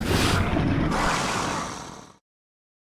curse.ogg